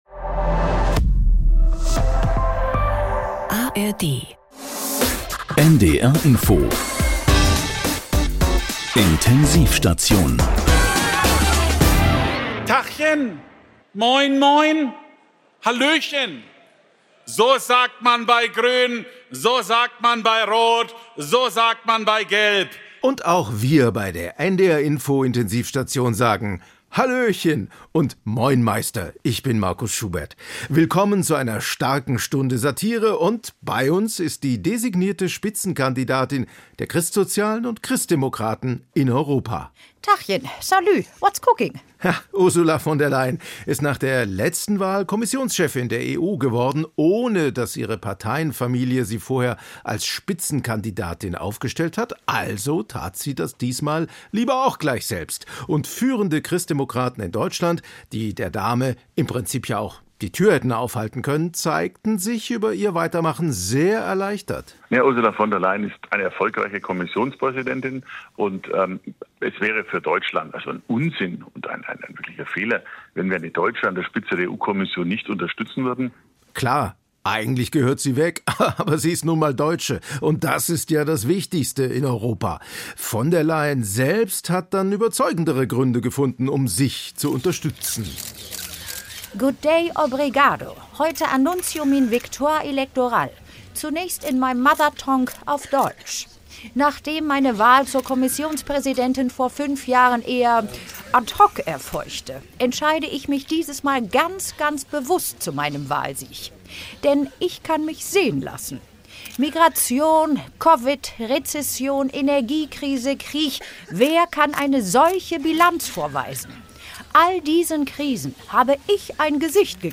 Von Bombenstimmung und Ampelfrust ~ Intensiv-Station - Satire von NDR Info Podcast